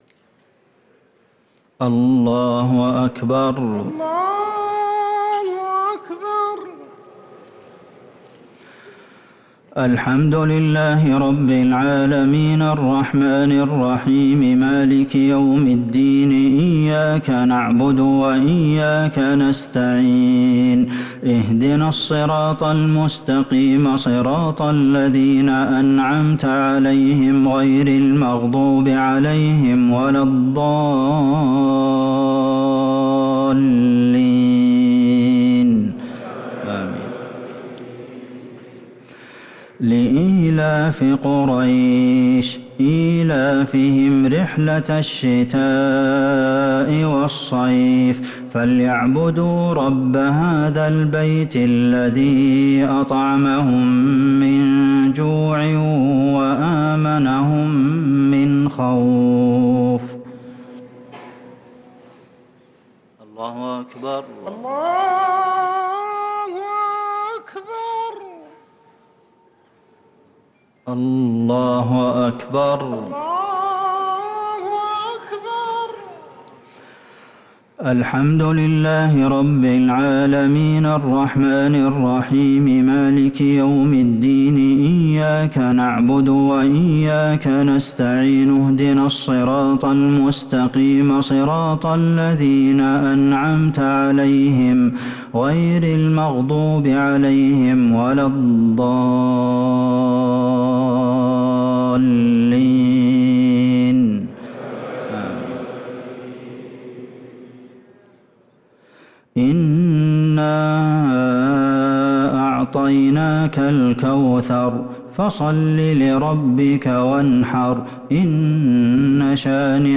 صلاة التراويح ليلة 12 رمضان 1444 للقارئ عبدالمحسن القاسم - الشفع والوتر - صلاة التراويح